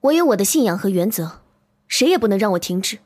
girl_04.mp3